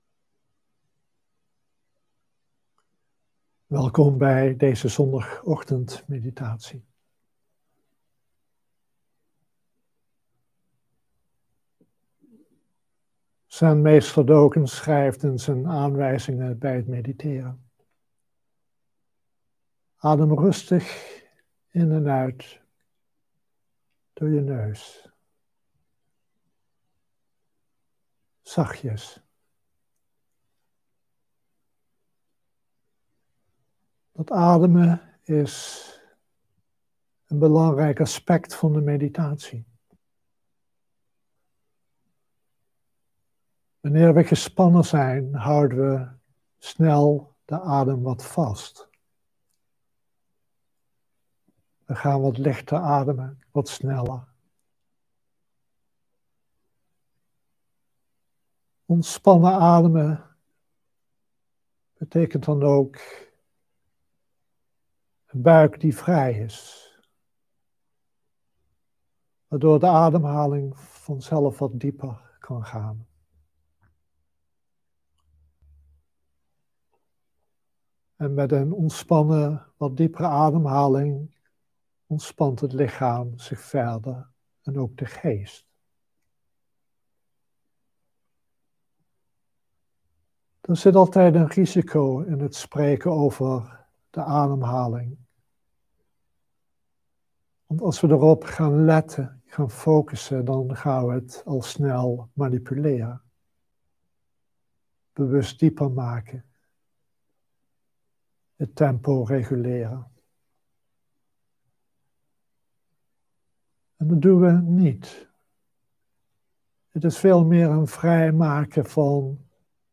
Dharma-onderwijs
Livestream opname